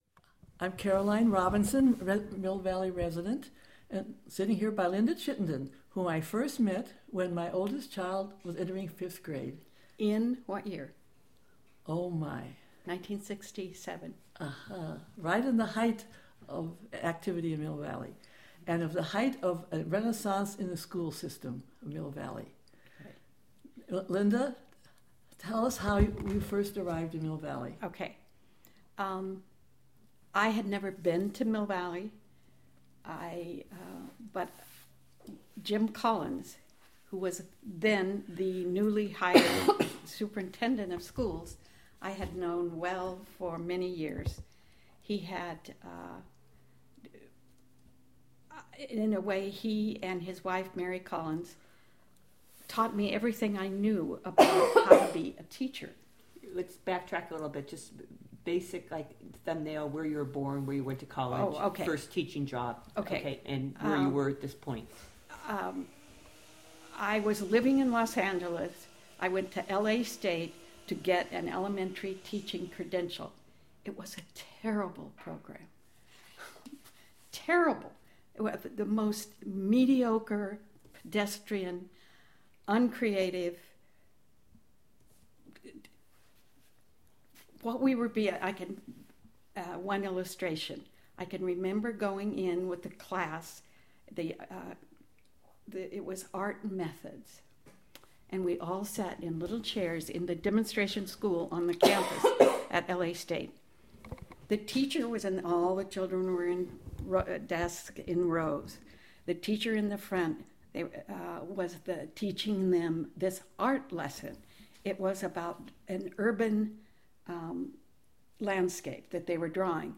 Oral History